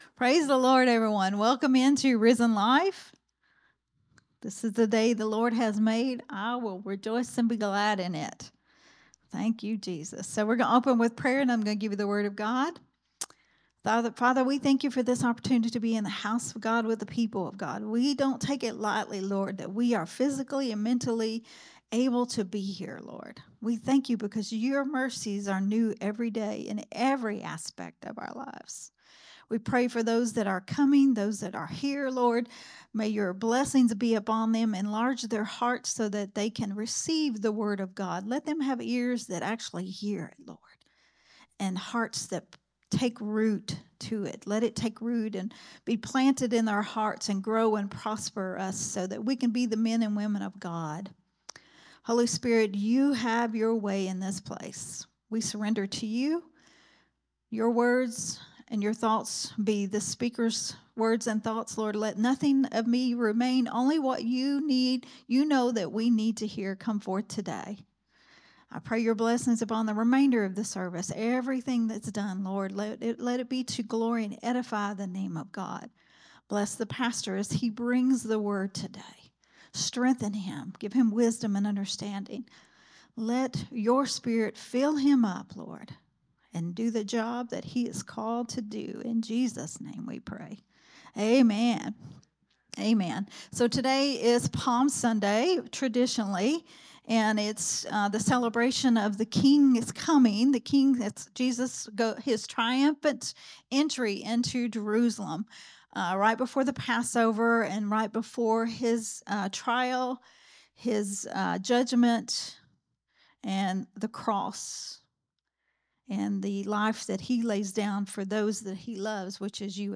Risen Life Growth Temple Ministries